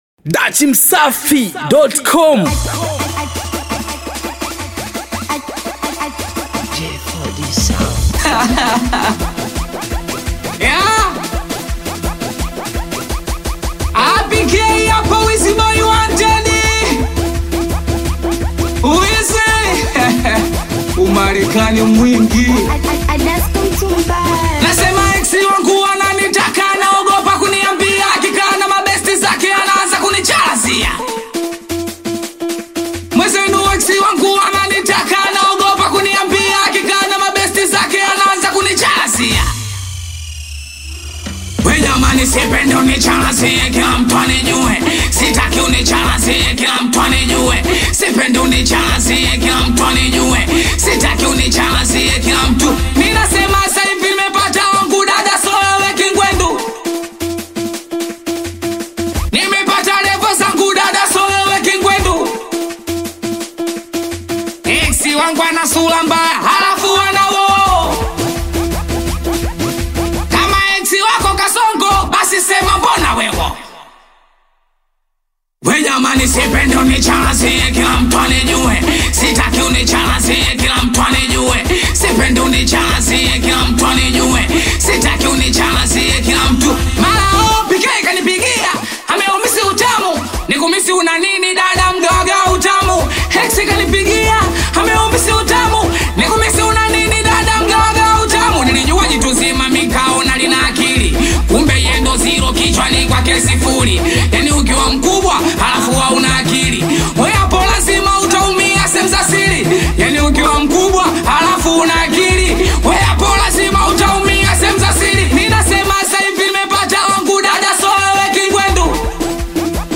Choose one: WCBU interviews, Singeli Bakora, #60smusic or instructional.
Singeli Bakora